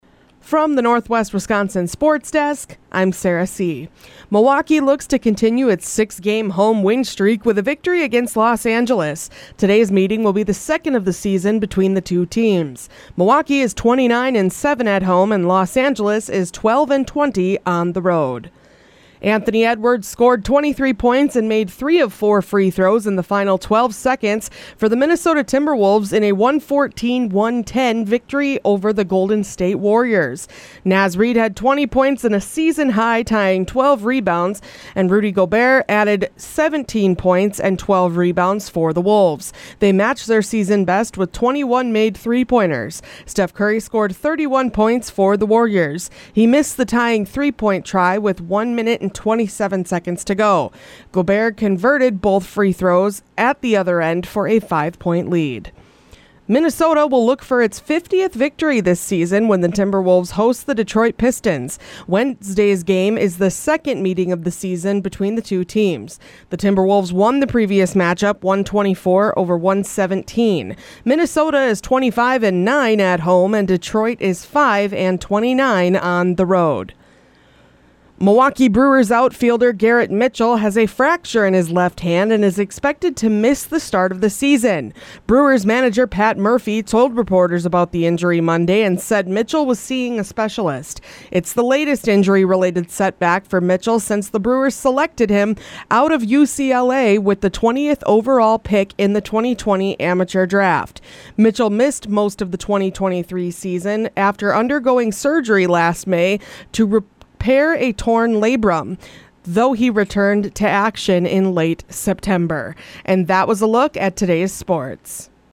Today’s sportscast from the Northwest Wisconsin Sports Desk: